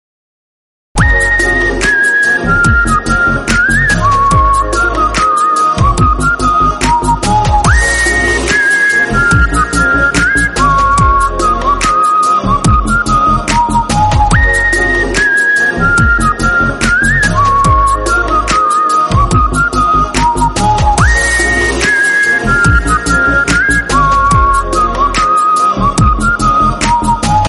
Category: Flute Ringtones